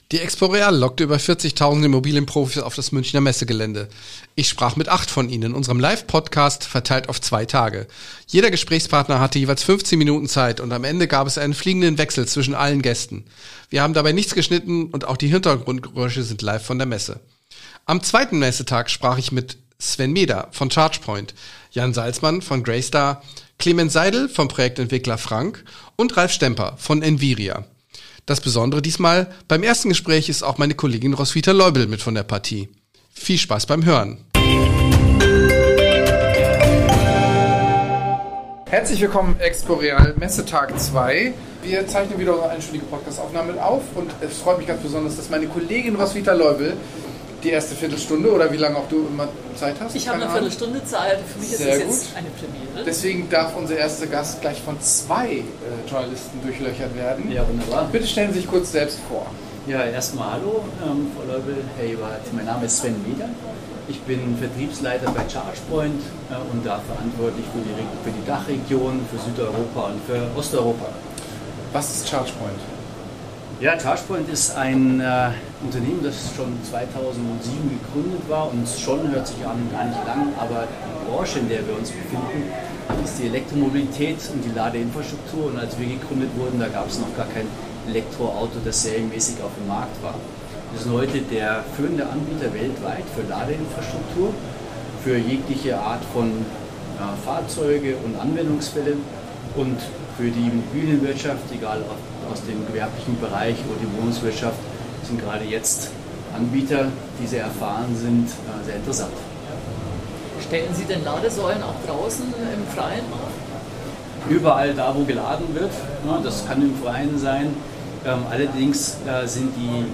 Beschreibung vor 1 Jahr Jeder Gesprächspartner hatte jeweils 15 Minuten Zeit, und am Ende gab es einen fliegenden Wechsel zwischen allen Gästen. Wir haben dabei nichts geschnitten, und auch die Hintergrundgeräusche sind live.